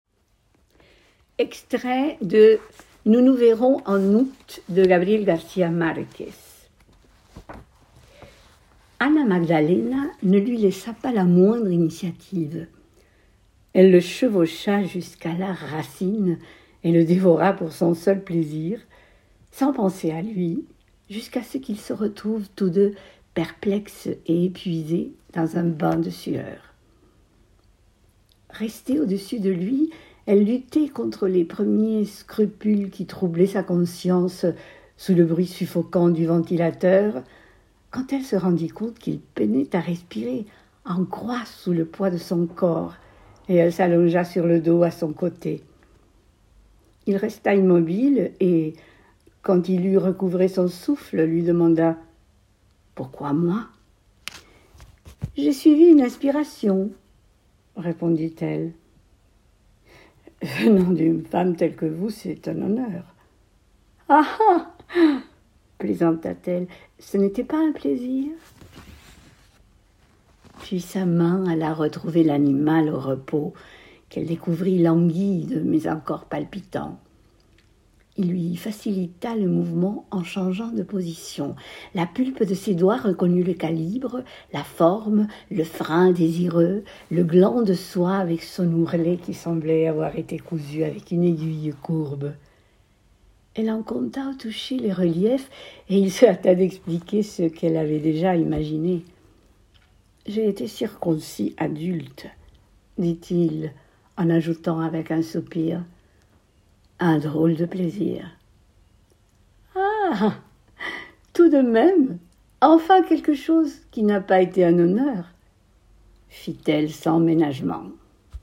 CHANSONS